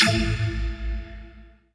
voiTTE64023voicesyn-A.wav